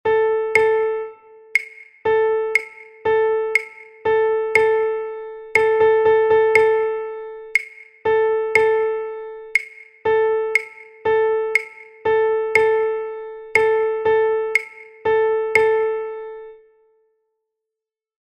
Look at the image and listen to the sound of the melody to assimilate offbeat.